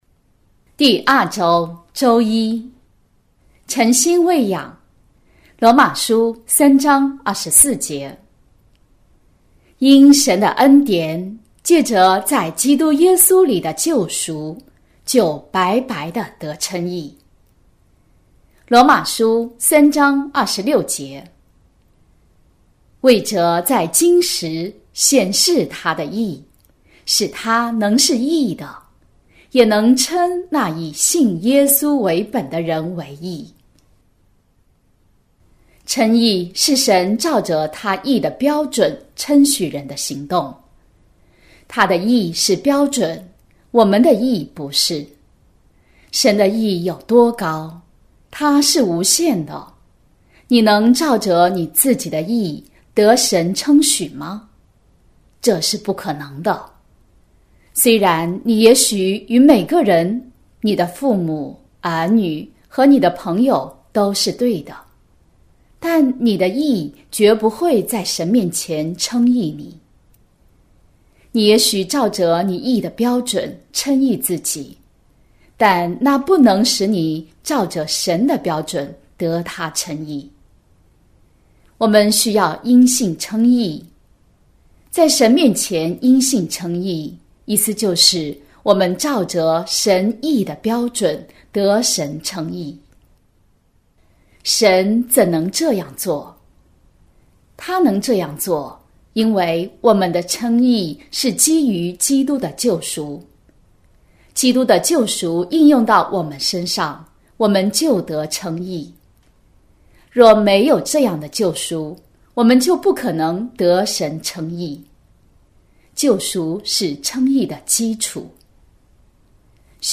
晨興聖言(中英文朗讀)